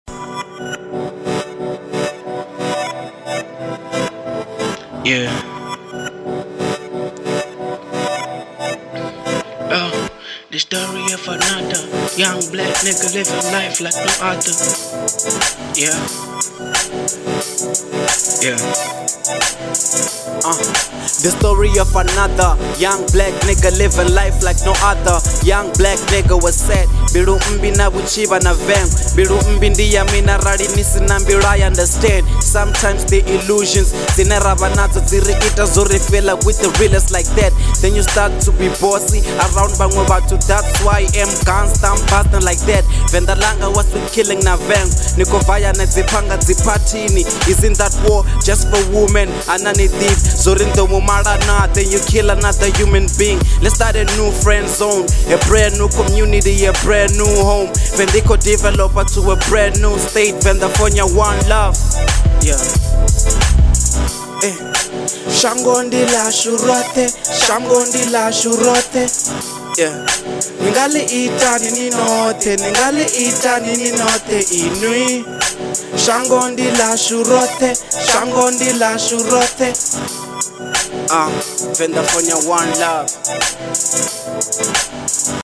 01:25 Genre : Venrap Size